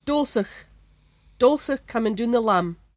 [dOHl-soock: dOHl-sook cum-een doon th’lUHm]